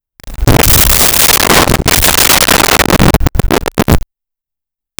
Monster Footsteps 01
Monster Footsteps 01.wav